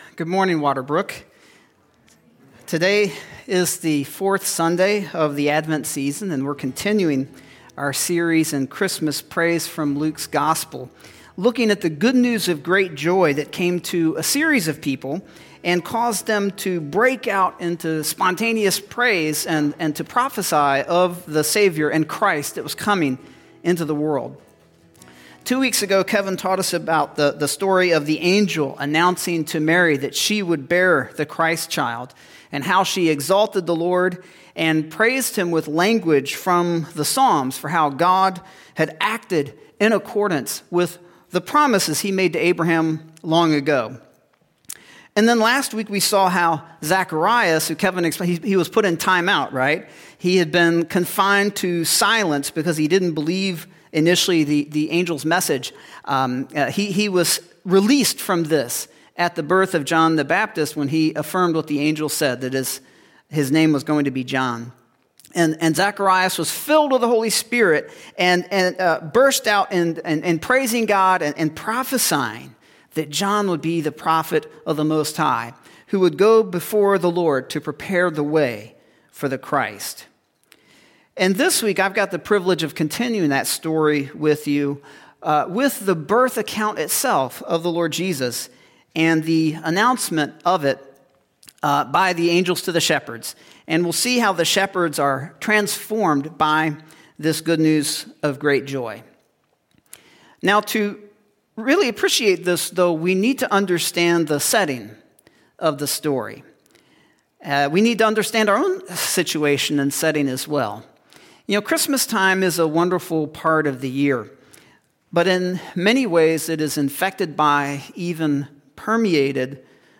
Sermons | Waterbrooke Christian Church